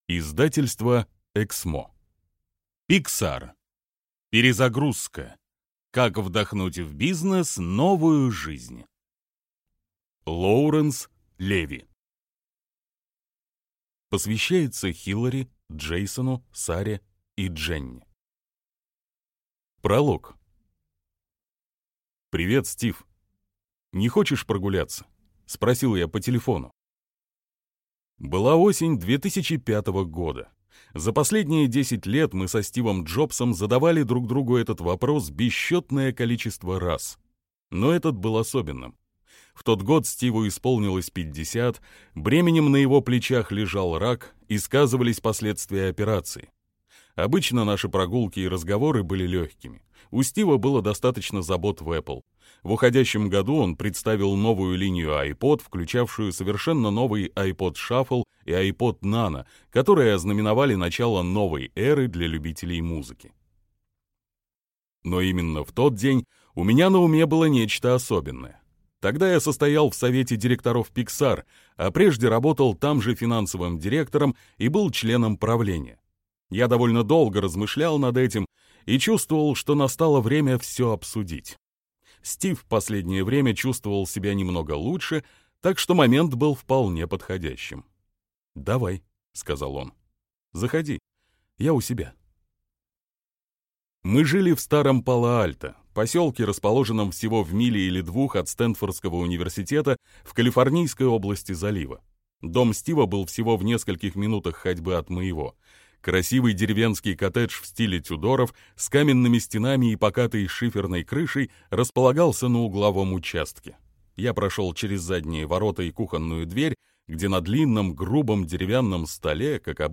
Аудиокнига PIXAR. Перезагрузка. Как вдохнуть в бизнес новую жизнь | Библиотека аудиокниг